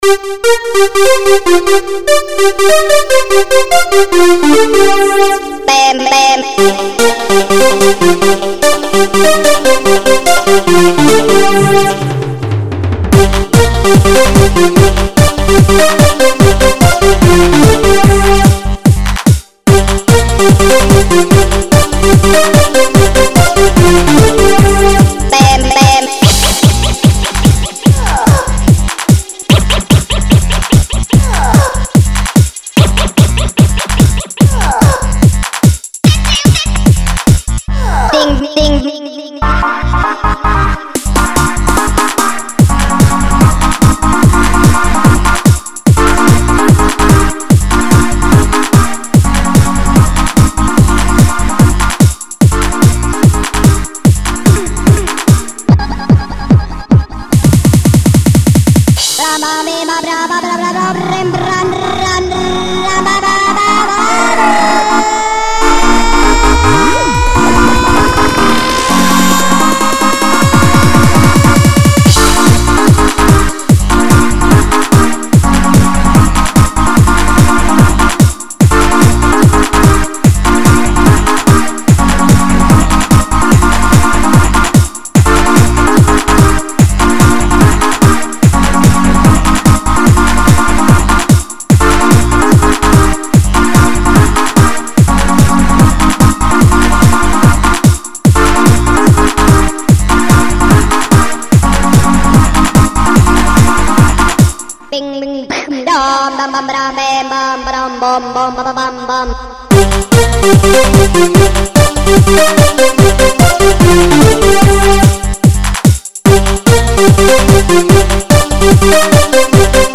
(Ремикс) PR